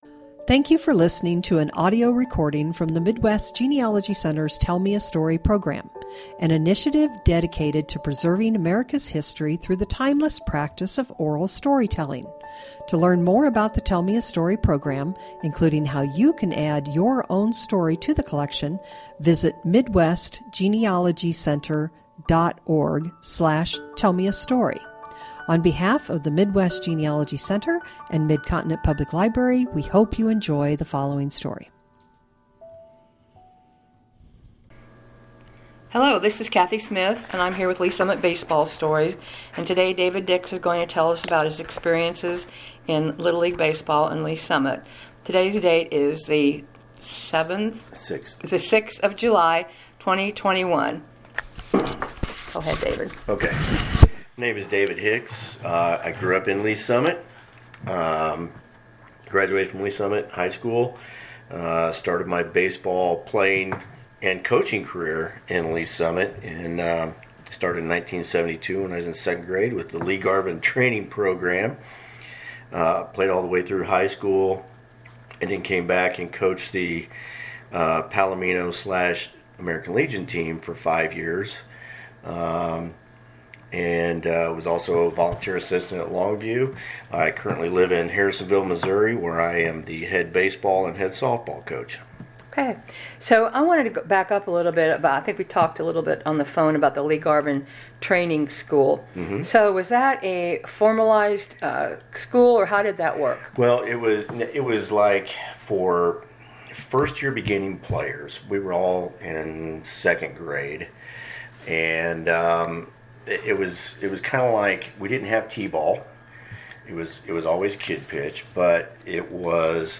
Baseball Coaches